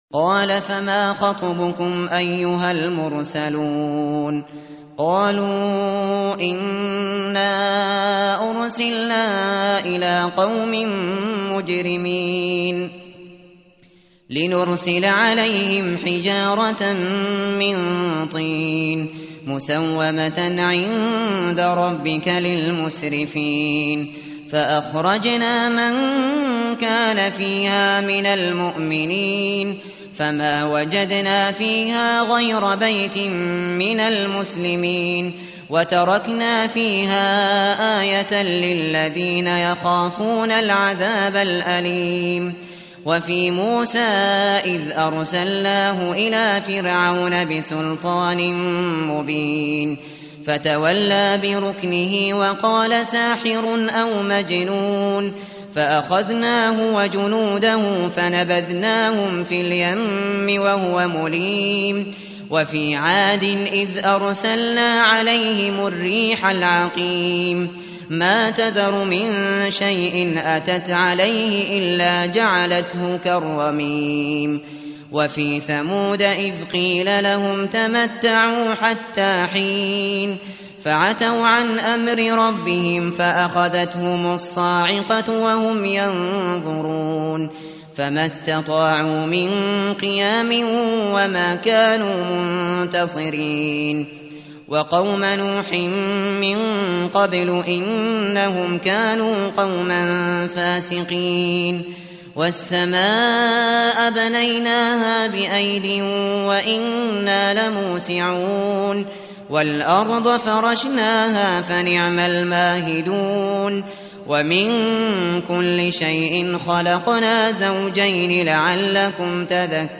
tartil_shateri_page_522.mp3